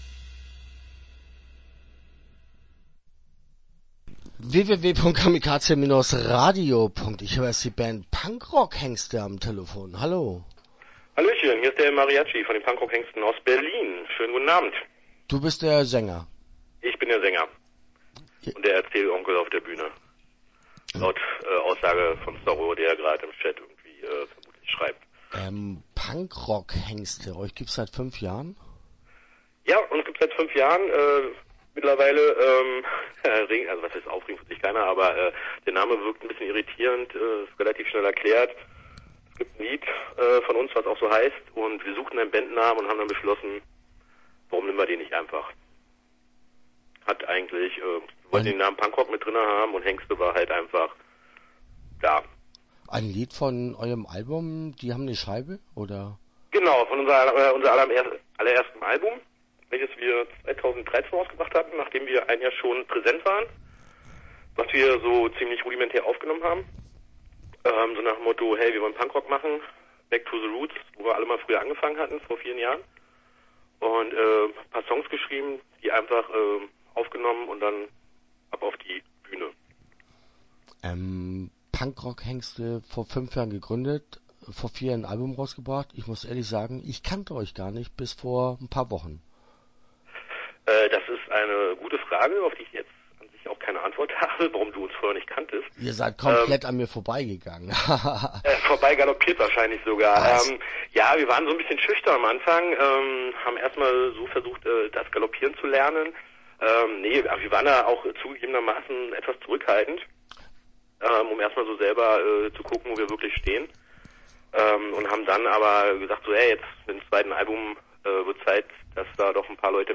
Punkrockhengste - Interview Teil 1 (10:21)